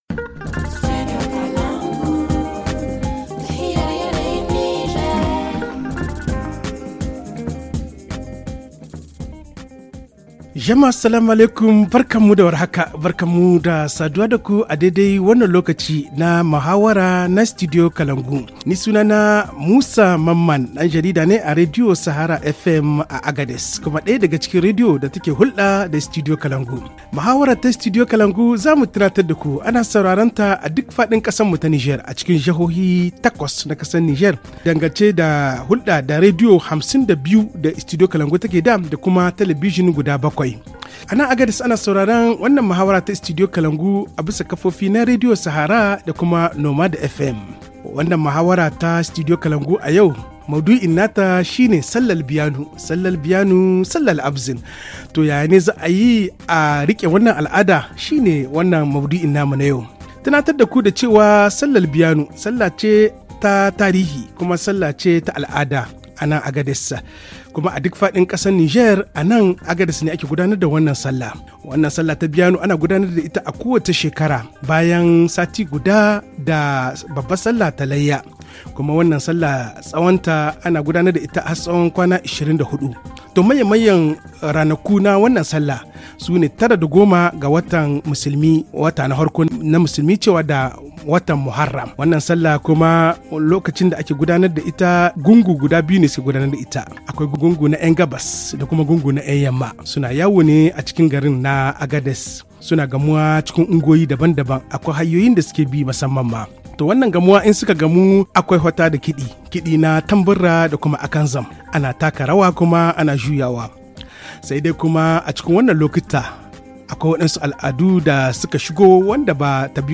HA Le forum en haoussa https